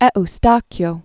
(ĕ-stäkyō), Bartolomeo c. 1510-1574.